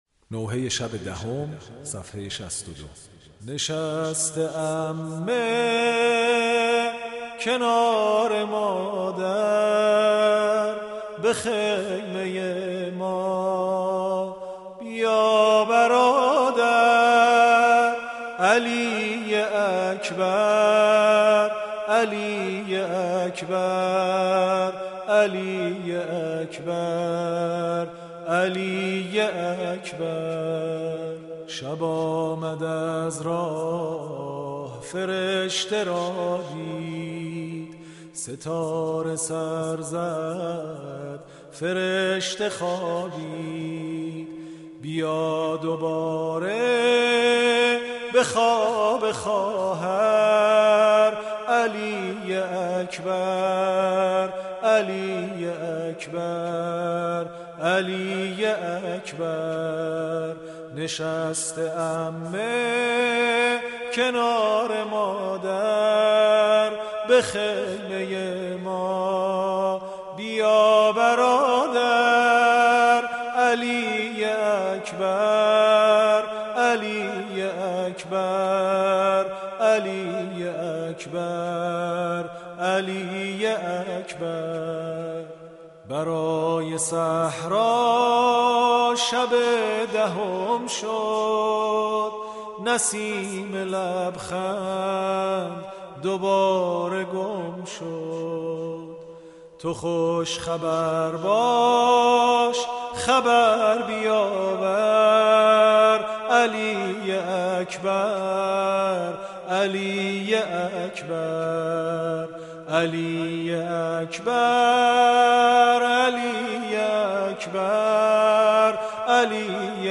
اشعار شهادت حضرت علی اکبر(ع),(ببین چه ماه تابان شـده مـه شهیــدان)به همراه سبک سنتی